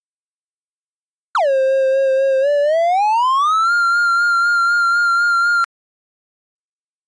• O son sobe; comeza grave e faise máis agudo.
son_subindo.mp3